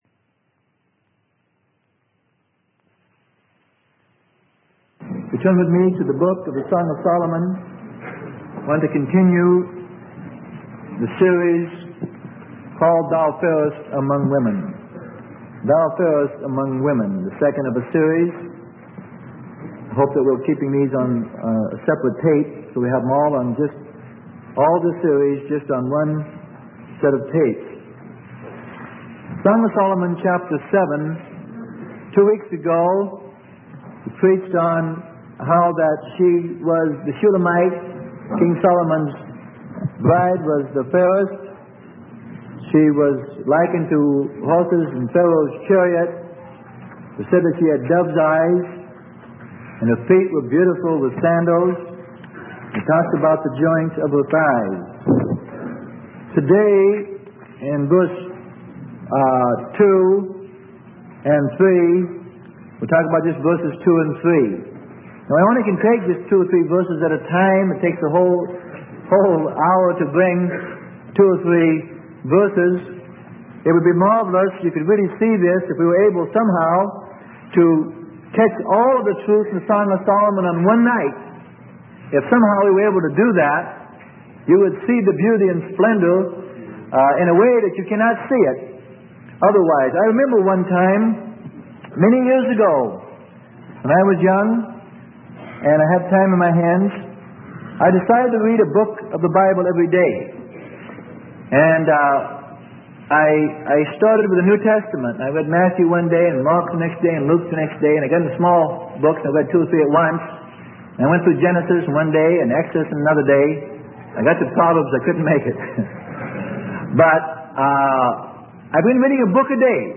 Sermon: Bride of Christ - Freely Given Online Library